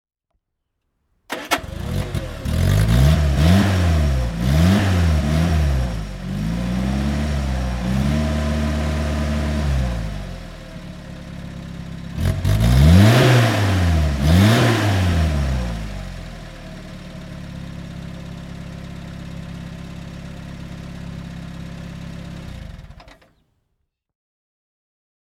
Sunbeam Imp (1969) - Starten und Leerlauf